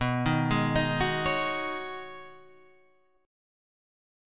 B6add9 chord